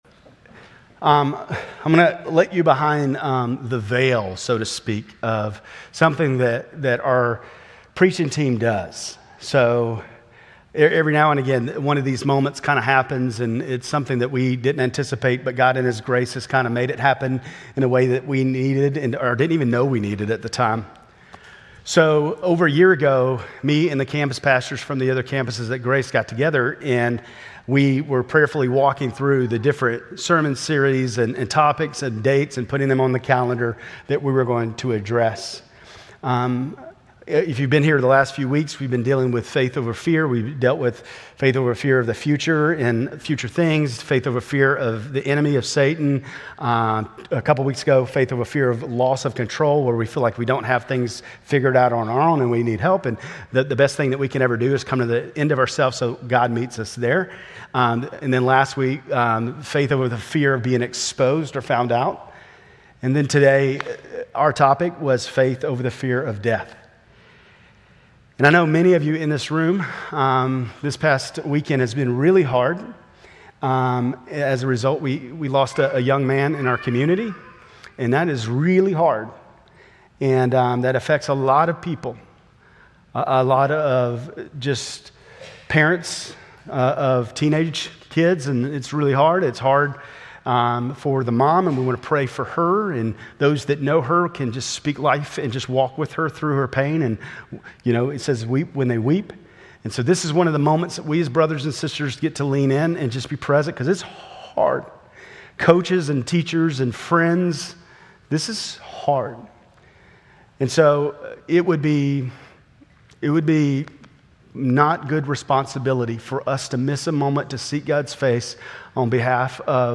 Grace Community Church Lindale Campus Sermons 5_25 Lindale Campus May 26 2025 | 00:33:56 1x 00:00 / 00:33:56 Subscribe Share RSS Feed Share Link Embed